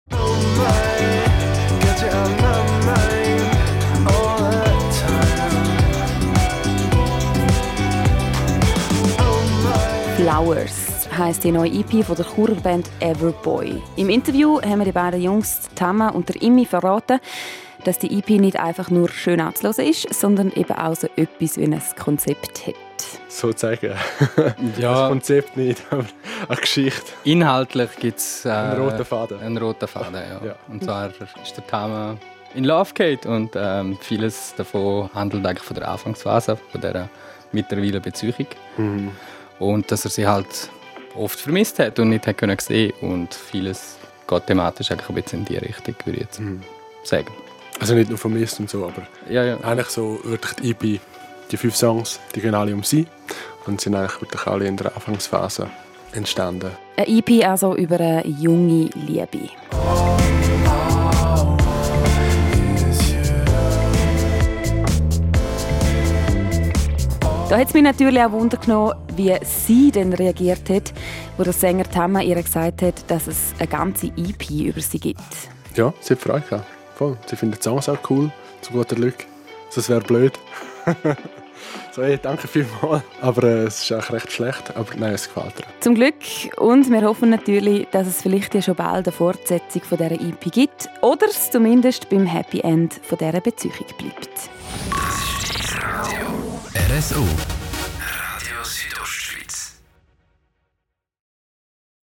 Die Churer Band Everboy hat eine neue EP herausgebracht. Wir haben sie zum Interview getroffen.